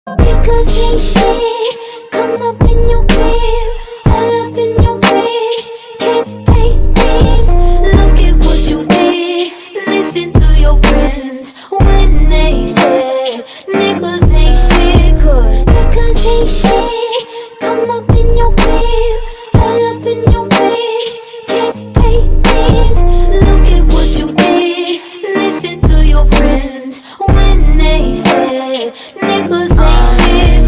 It blends smooth R&B melodies with hip-hop elements